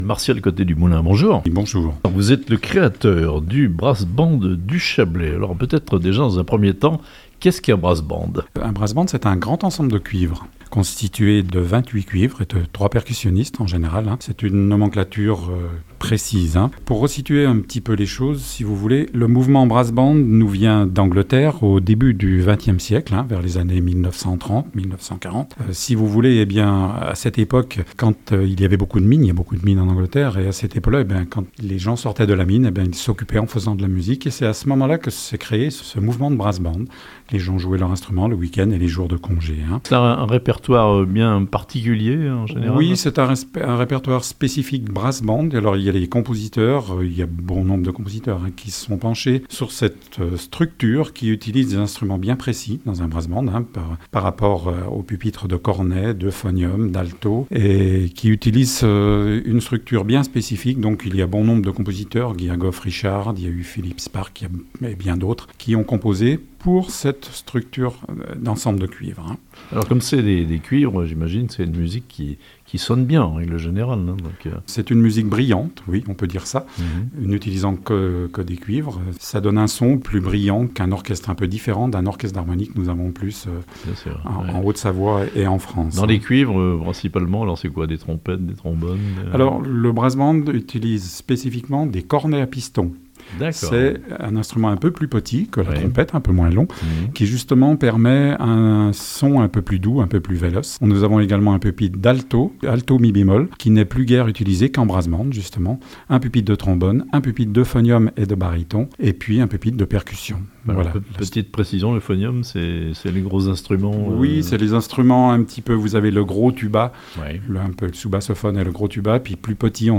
Un "brass band" pour le Chablais, et il donnera bientôt un concert à la Cité de l'Eau à Amphion (interview)